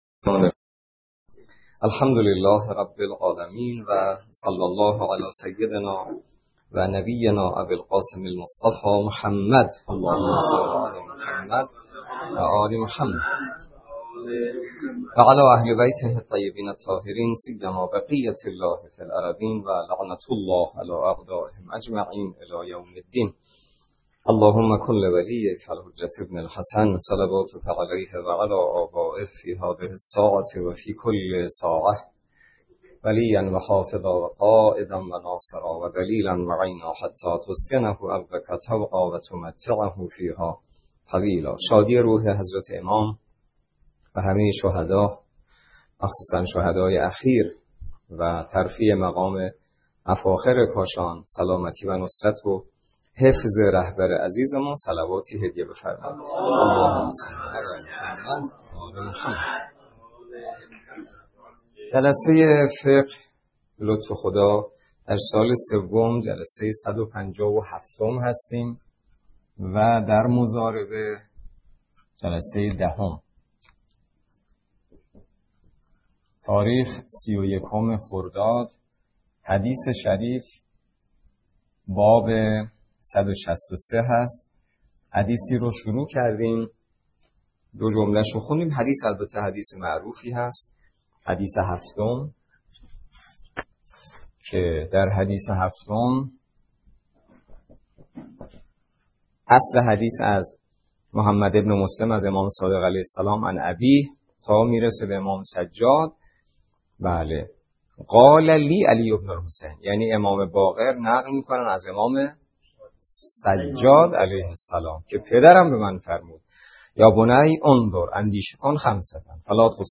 درس فقه